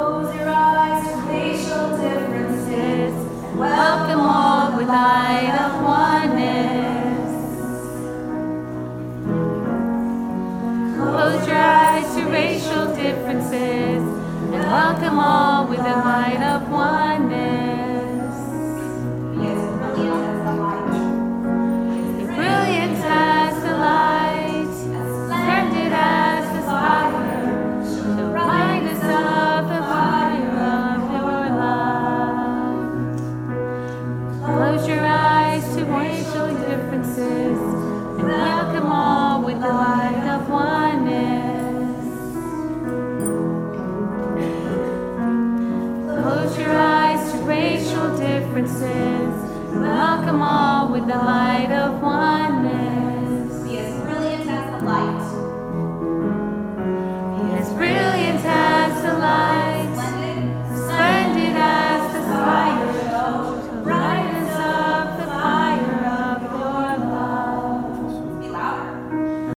This particular quote has been made into a song that is frequently sung in Baha’i gatherings. Unfortunately, there is not a high quality recording of the song available, so we went with the best we had!- a recording made at a Baha’i summer school event.